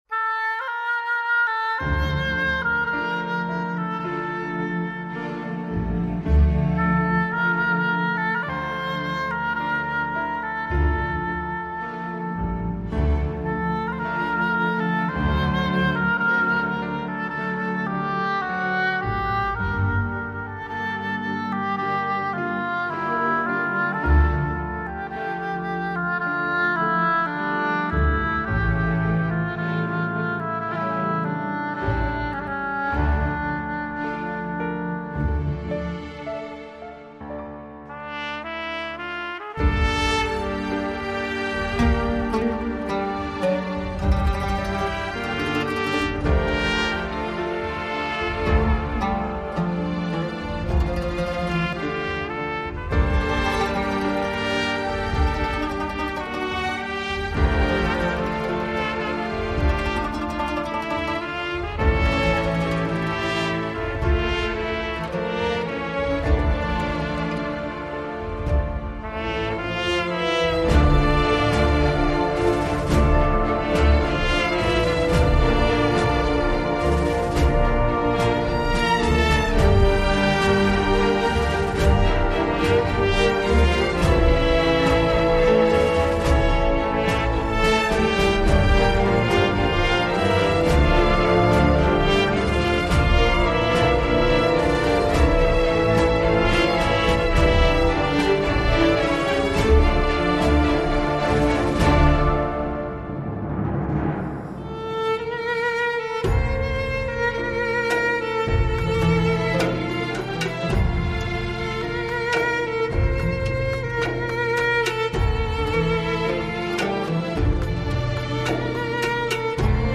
با نغمه‌هایی سرشار از احساس و عشق